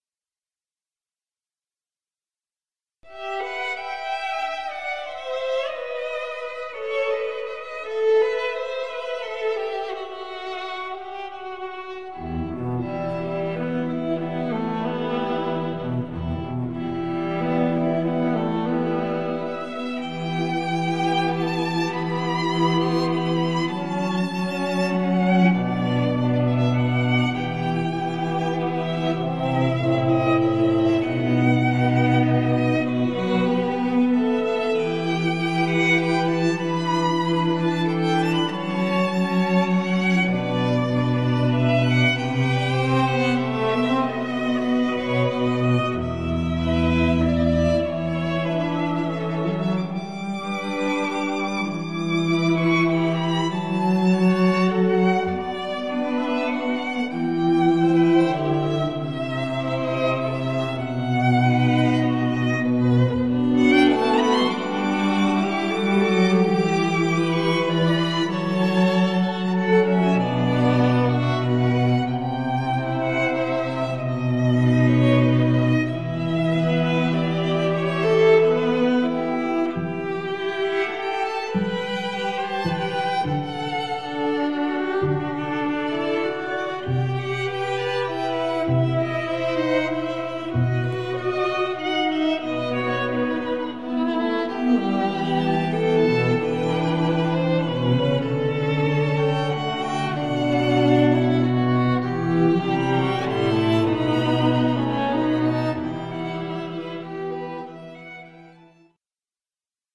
ELEGANT STRING MUSIC FOR ANY OCCASION